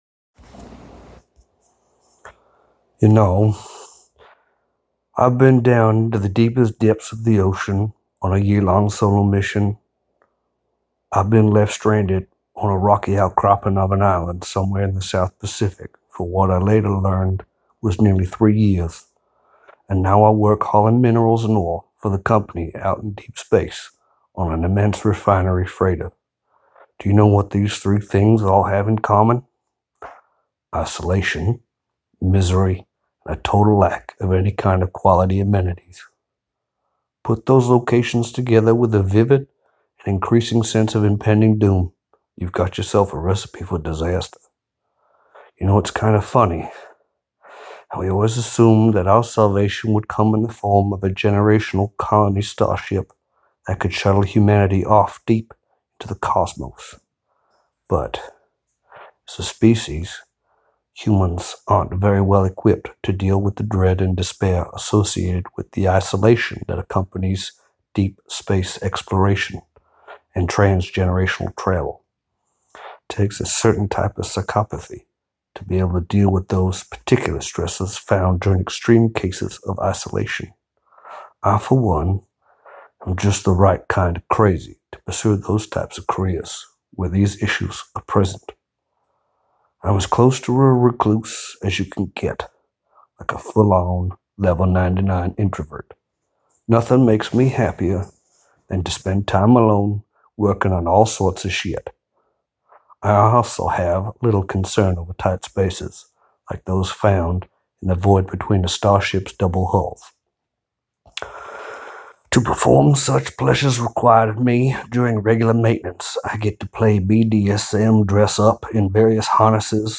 And for something different in these odd times, you can listen to me narrate this short story.